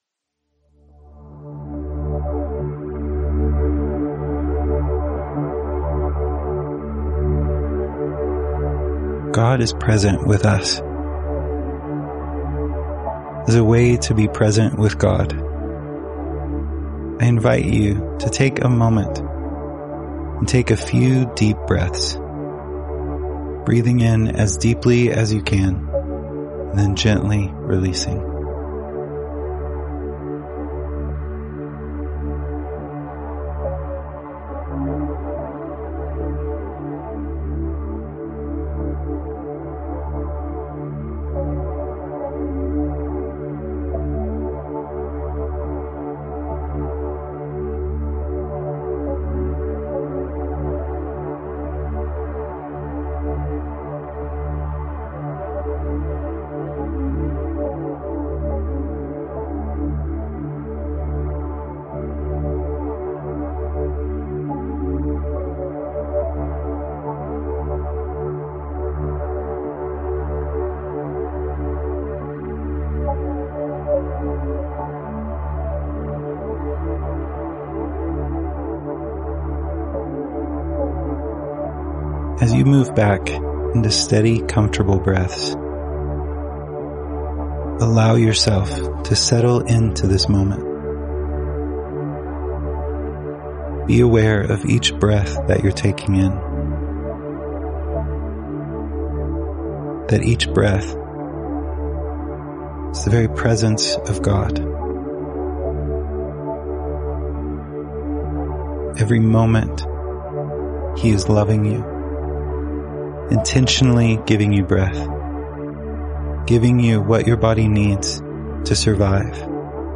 Welcome to your daily guided prayer session. Click the audio to experience guided prayer through today's passage — then, take a moment with the daily breath prayer.